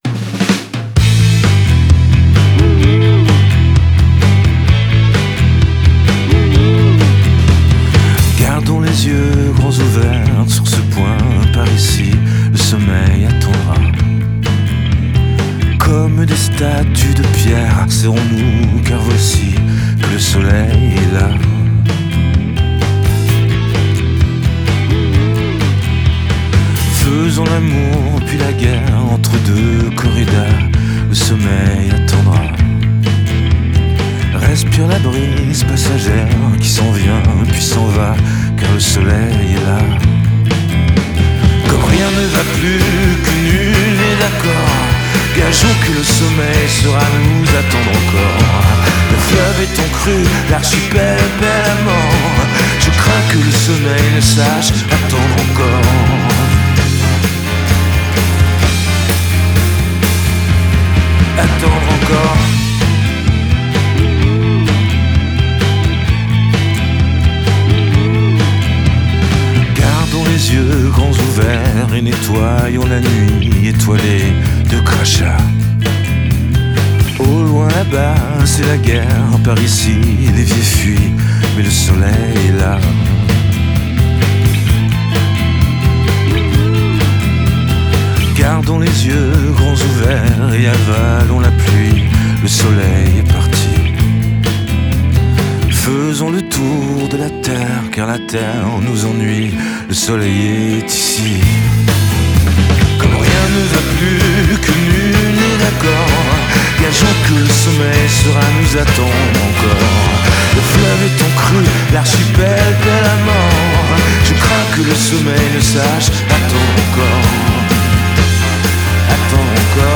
Genre: Pop, Chanson, French